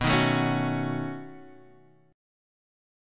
B9 Chord